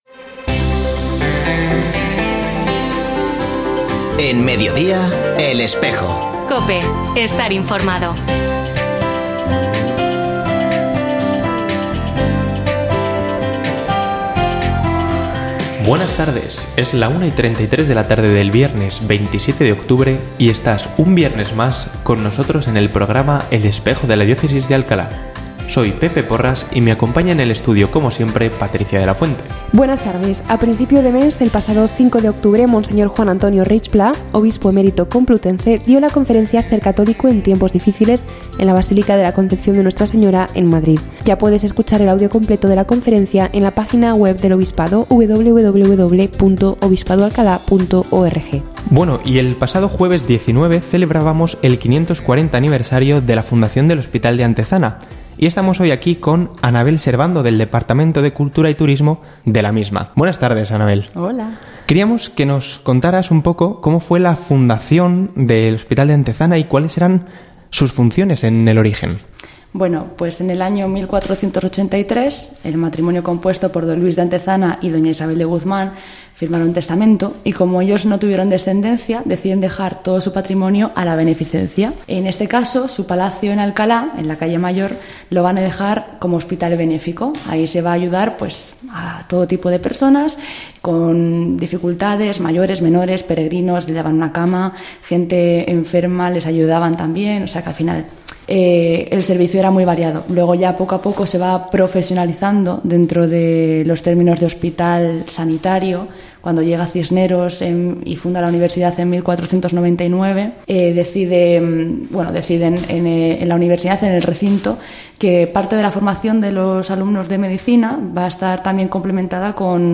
Ofrecemos el audio del programa de El Espejo de la Diócesis de Alcalá emitido hoy, 27 de octubre de 2023, en radio COPE. Este espacio de información religiosa de nuestra diócesis puede escucharse en la frecuencia 92.0 FM, todos los viernes de 13.33 a 14 horas.
Y en nuestra sección semanal «El minuto del Obispo» , Mons. Prieto Lucena nos habla de la necesidad de orar por la paz, invitándonos a todos a acudir a María con el rezo del Rosario por esta intención. Al igual que todos los viernes, también ofrecemos otras noticias y eventos de la vida de nuestra Iglesia local.